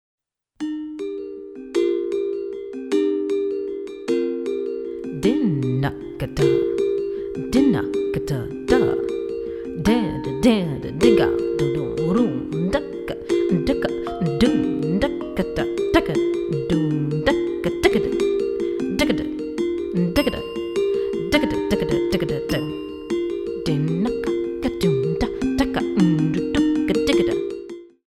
Voice, Handsonic